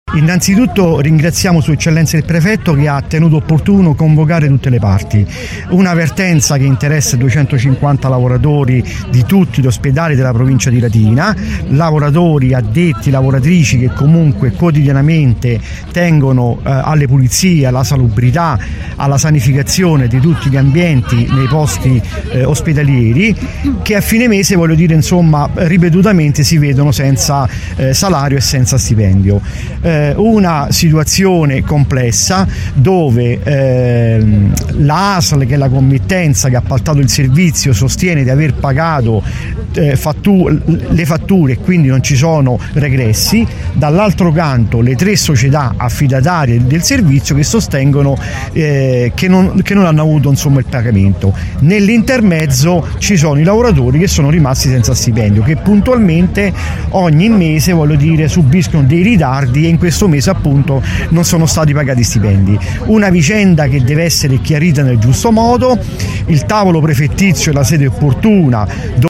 Qui, le dichiarazioni